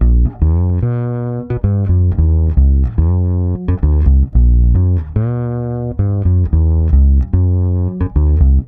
-AL AFRO C.wav